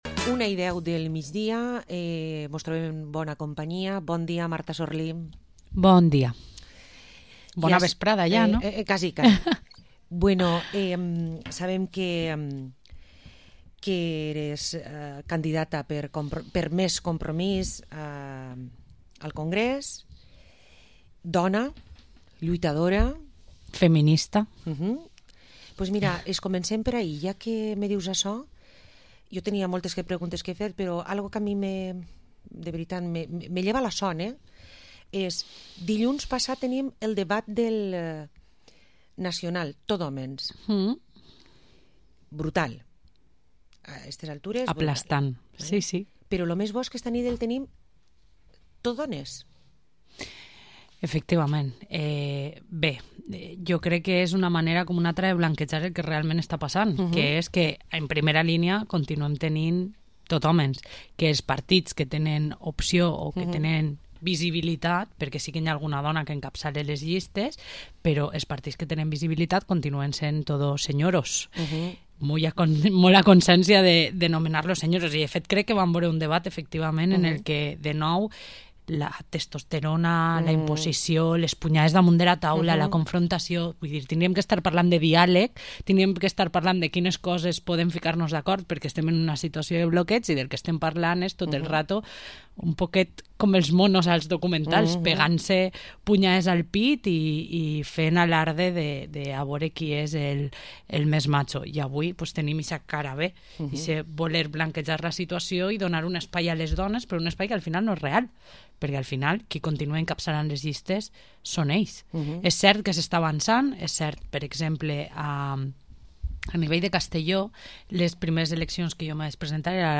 Entrevista Marta Sorlí, cabeza lista de Més Compromís Castellón al Congreso. 07 de noviembre del 2019